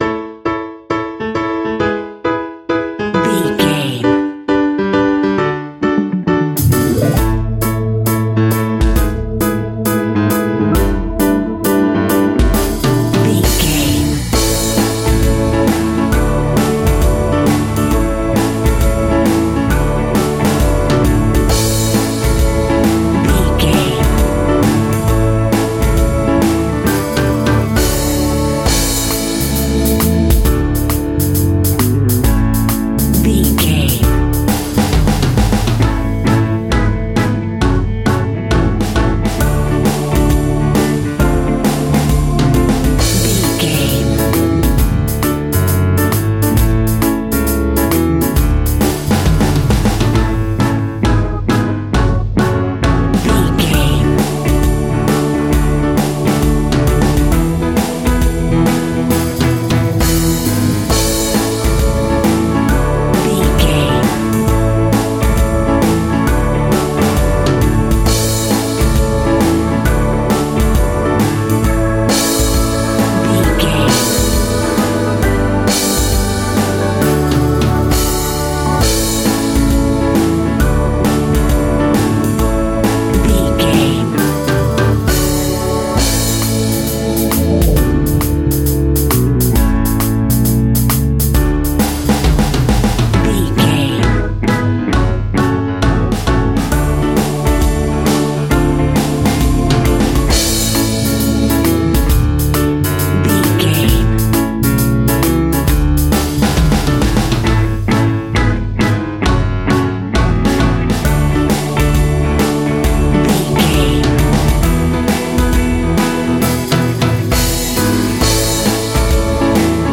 Happy Power Pop Music Cue.
Ionian/Major
pop rock
energetic
uplifting
acoustic guitar
drums
piano